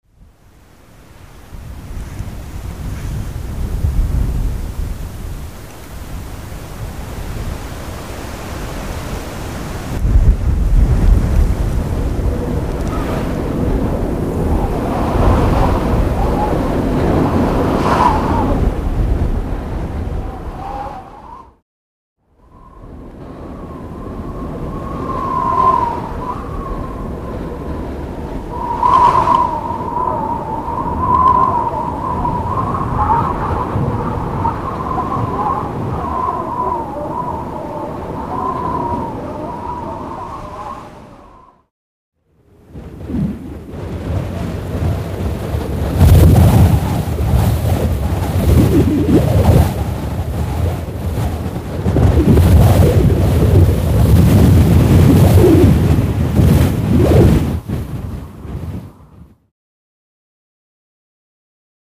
Porozmawiajcie z dziećmi na temat wiersza i posłuchajcie nagrań wiatru i deszczu (zagadki słuchowe)
Odgłosy-wiatru.mp3